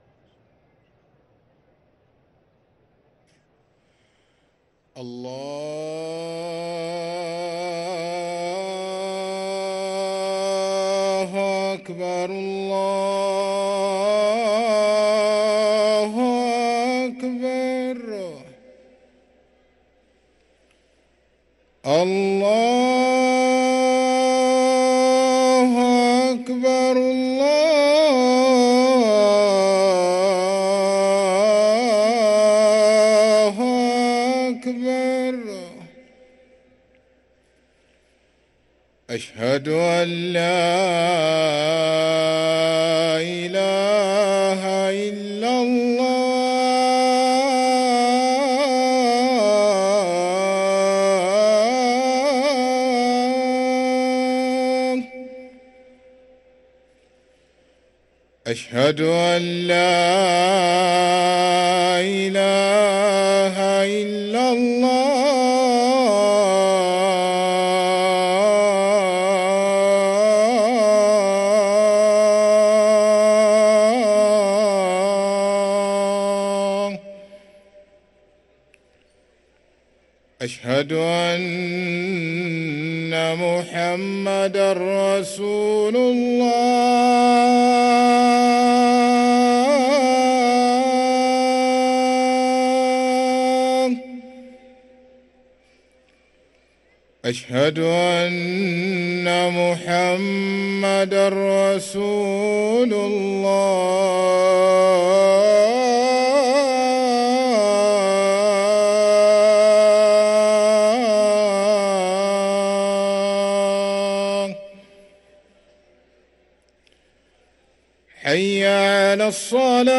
أذان العصر للمؤذن سعيد فلاته السبت 10 رمضان 1444هـ > ١٤٤٤ 🕋 > ركن الأذان 🕋 > المزيد - تلاوات الحرمين